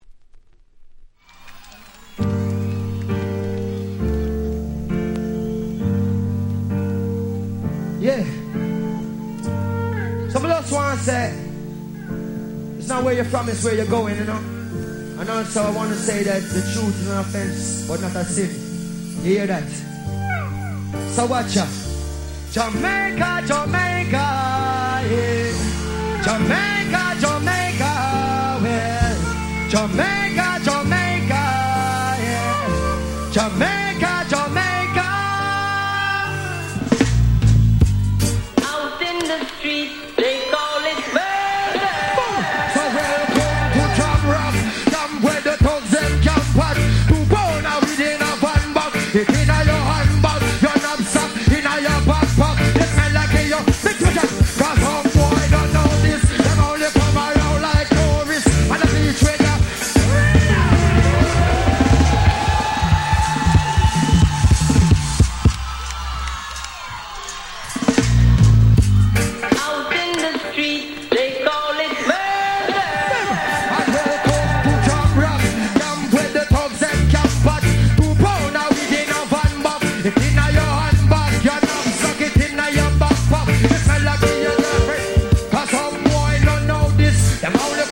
05' Super Hit Reggae !!
いやいや、このライブバージョン聴いちゃったらもう絶対にこっちでしょ。